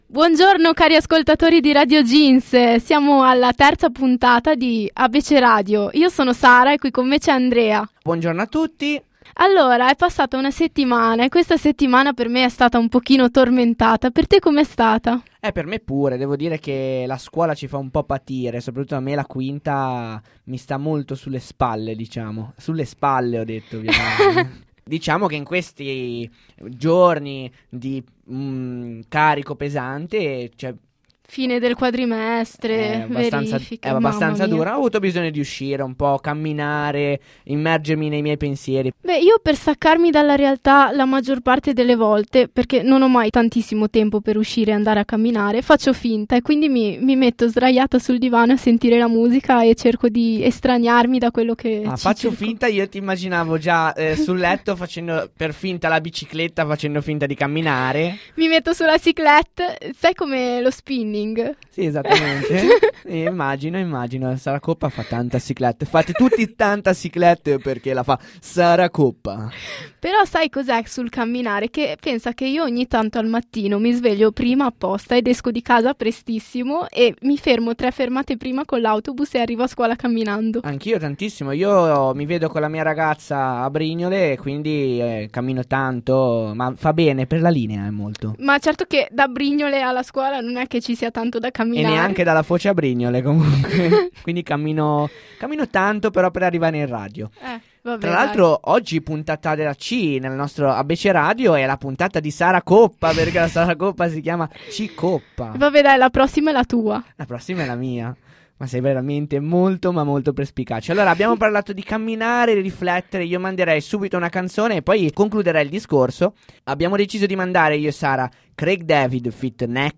play_circle_filled Abbeceradio - Lettera C Radioweb Cassini Questo programma procede esattamente come un Abbecedario (dal quale l'anagramma Abbeceradio): ogni puntata avrà una lettera dell'alfabeto e gli autori delle canzoni che verranno mandate avranno l'iniziale di quella lettera. Tutto questo farcito di divertimento, gag, intrattenimento e molto altro